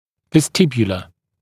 [vəs’tɪbjələ][вэс’тибйэлэ]вестибулярный, преддверный